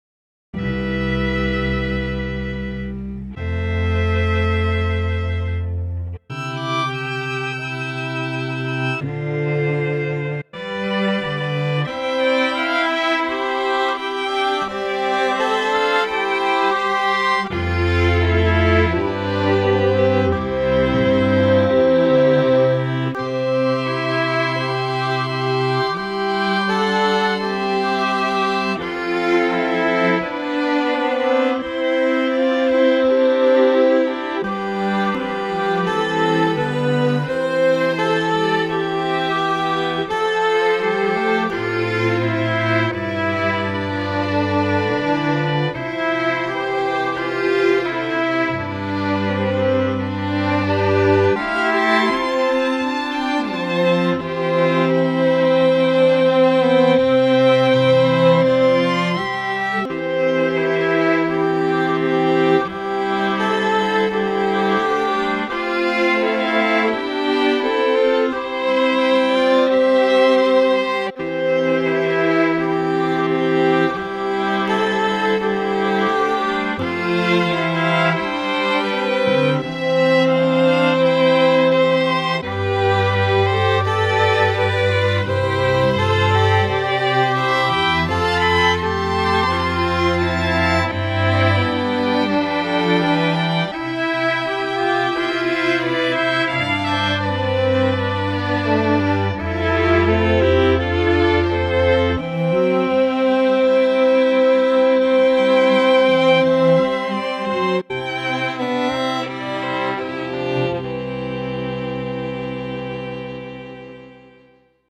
My backing has made up chords.